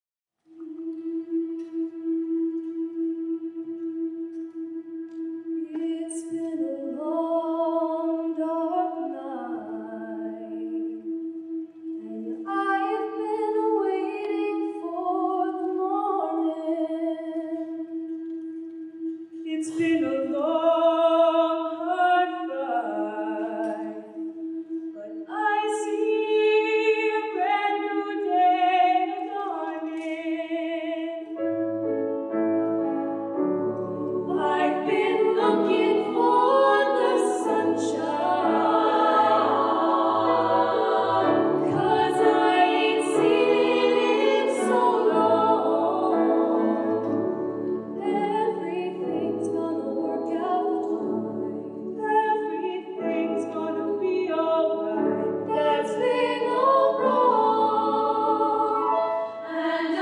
four curricular choirs and two a cappella ensembles
Sing With Heart: Spring Concert, 2019
With: Choral Union